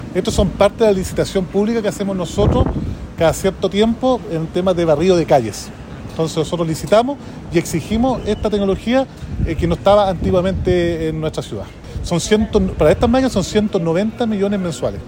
El alcalde de Temuco, Roberto Neira, dijo que la iniciativa corresponde a la licitación de barrido de calles, revelando que la inversión mensual es de $190 millones de pesos.